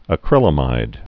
(ə-krĭlə-mīd)